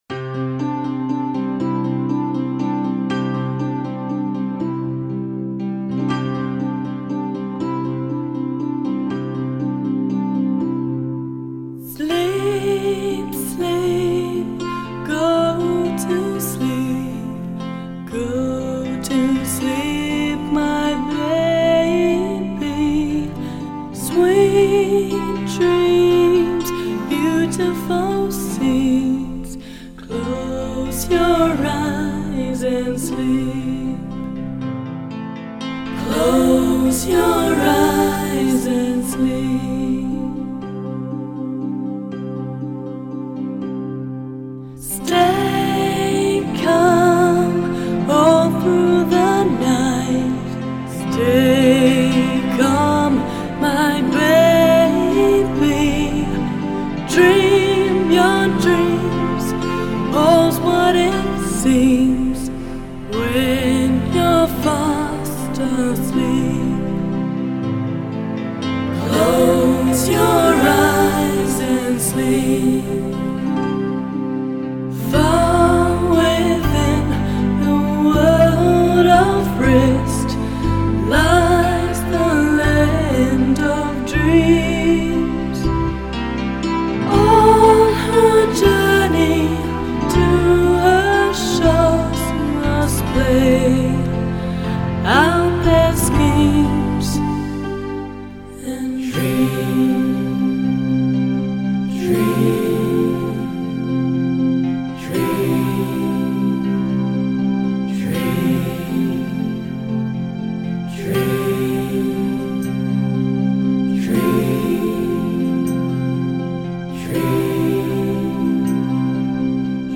a lullaby